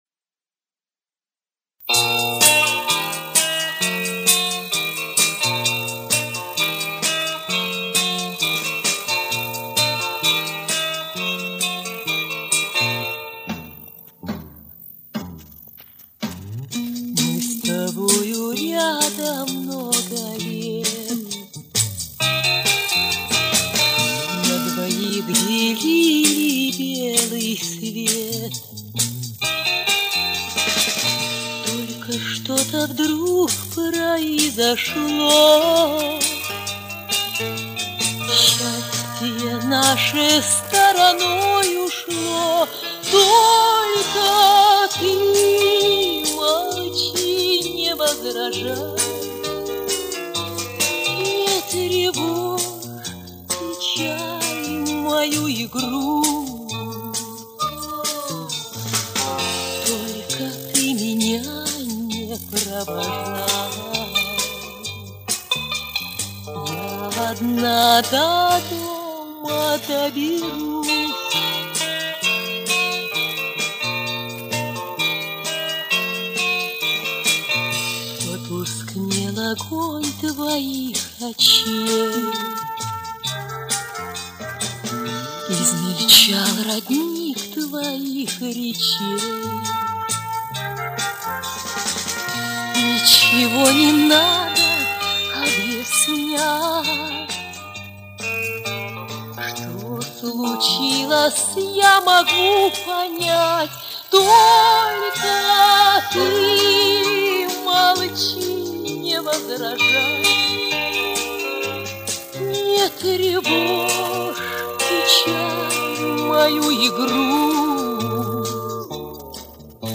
Убрал щелчки и увеличил громкость до нормального уровня